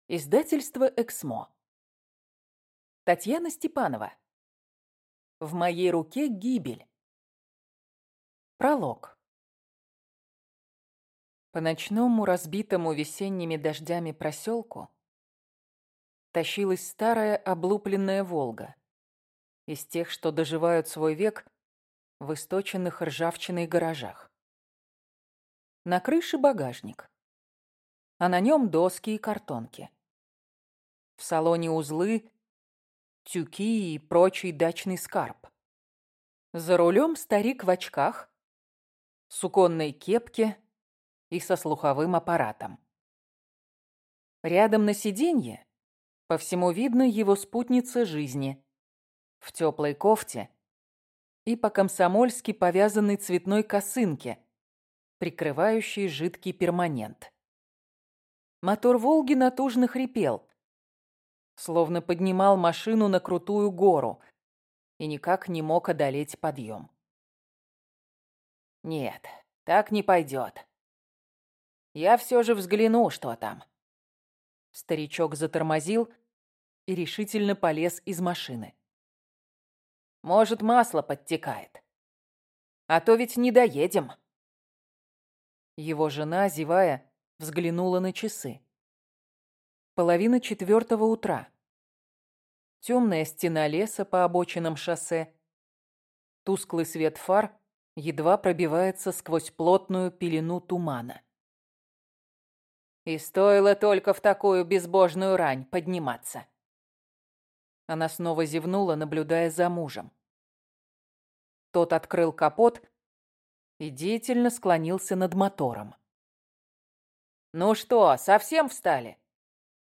Аудиокнига В моей руке – гибель | Библиотека аудиокниг